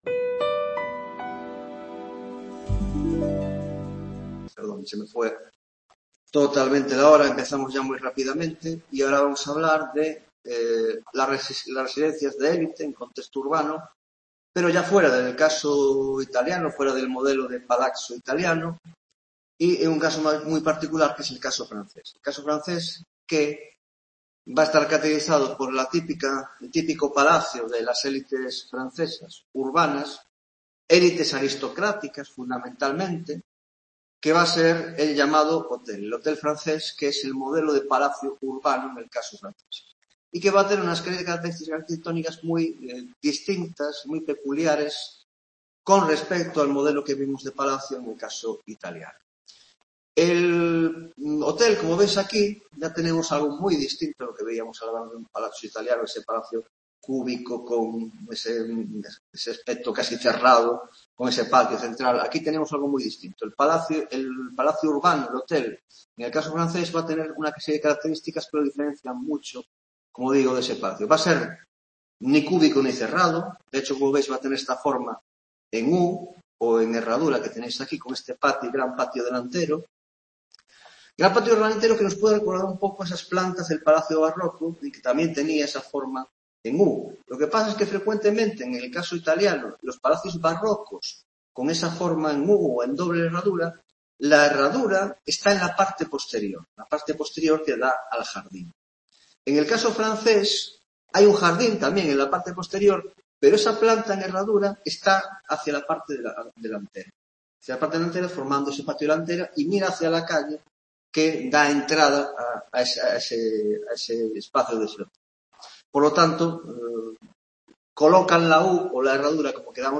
9ª Tutoría de Órdenes y Espacio en la Arquitectura Moderna - Modelos Arquitectónicos, El Palacio (2ª parte) - 1) El Hotel francés; 2) El palacio rural: La Villa y la Villa Suburbana